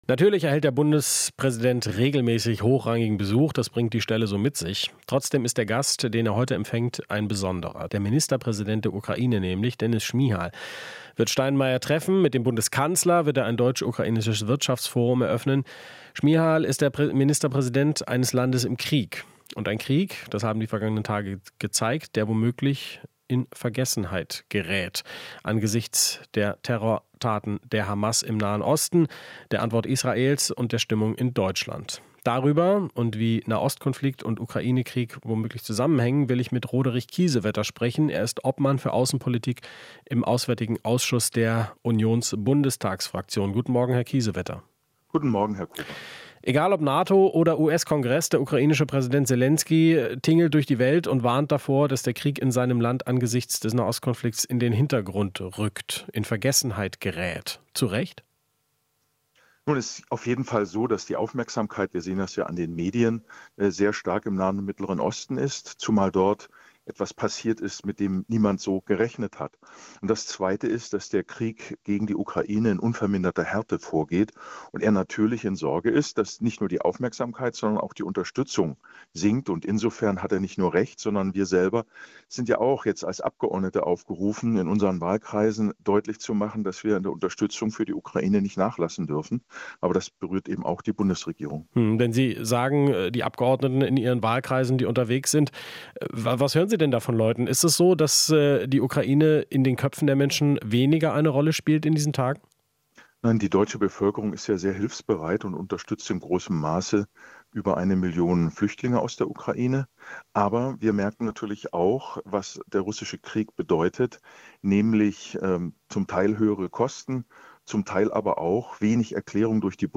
Interview - Kiesewetter (CDU): Bei Unterstützung für die Ukraine nicht nachlassen